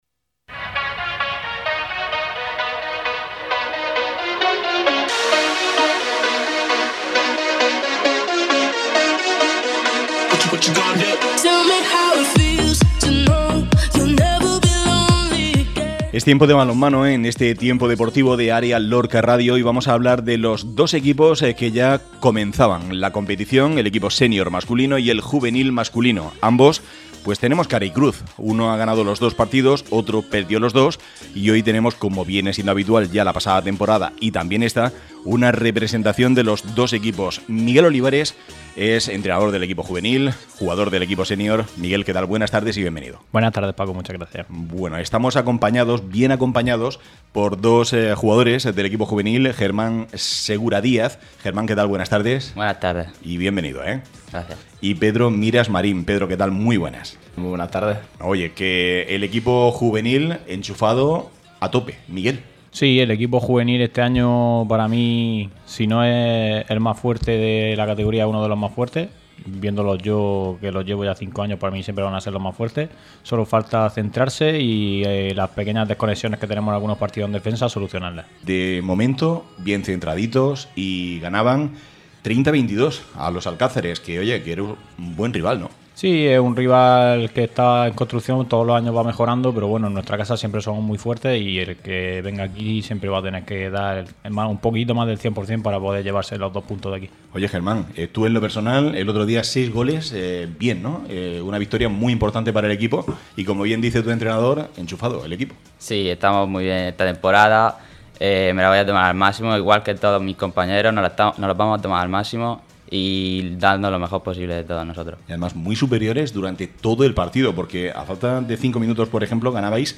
han pasado por el estudio de Área Lorca Radio en su programa deportivo